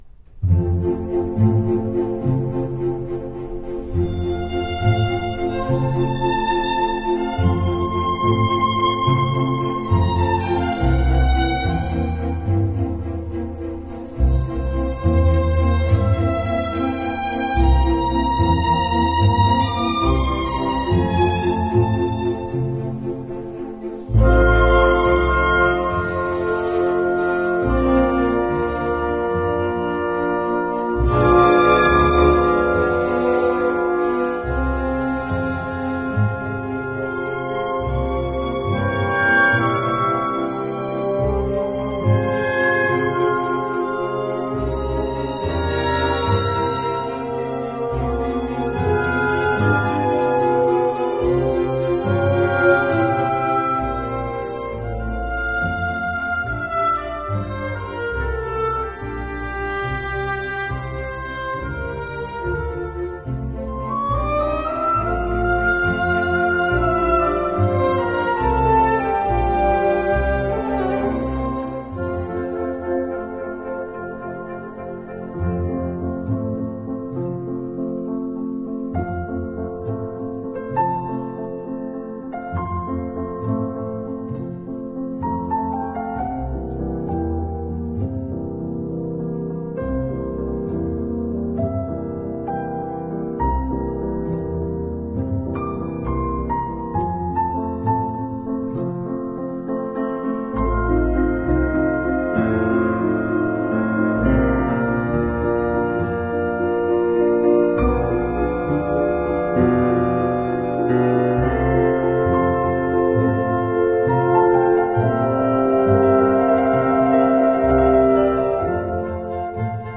Two full length works, and one slow movement, all by Mozart, in today’s programme!
Piano Sonata
Performed by Alfred Brendel
Performed by an unnamed orchestra and conductor